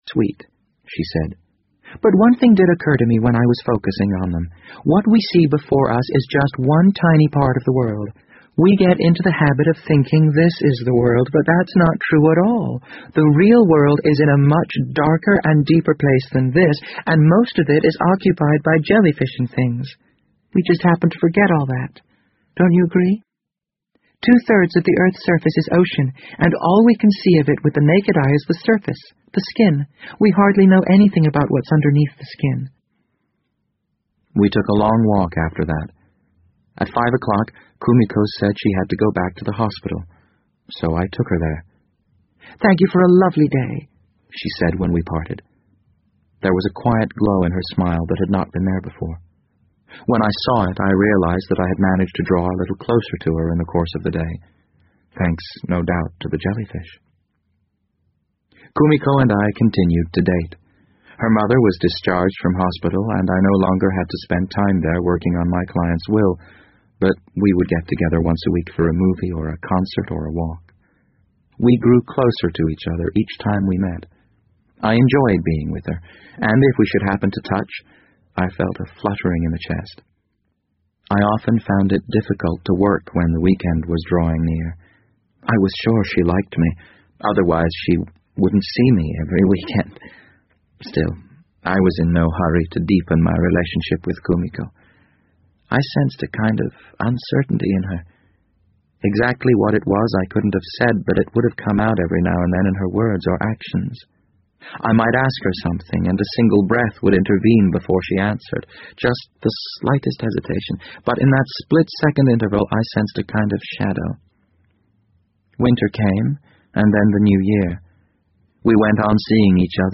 BBC英文广播剧在线听 The Wind Up Bird 006 - 12 听力文件下载—在线英语听力室